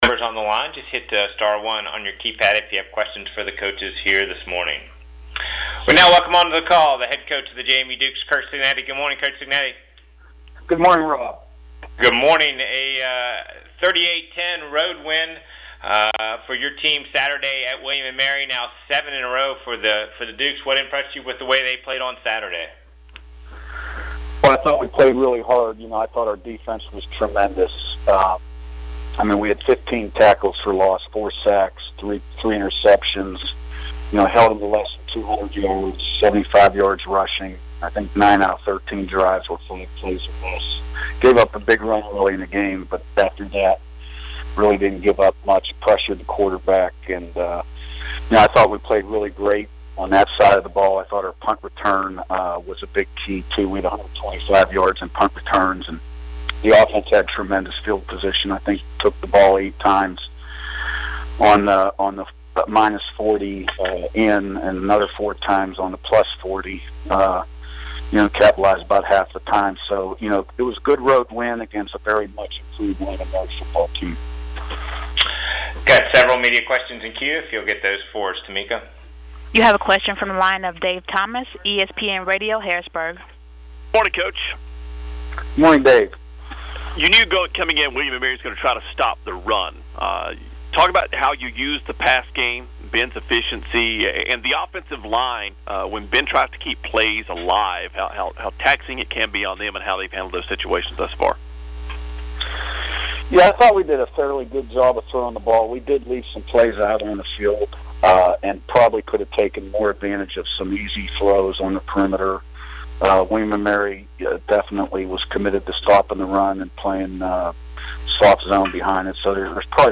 CAA COACHES TELECONFERENCE LISTEN: Head Coach Curt Cignetti